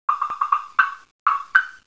Made from select grade Rock Maple and assorted hardwoods.
Supported on felt feet for maximum resonance.
Click on icon below to hear woodblocks.
woodblocks.wav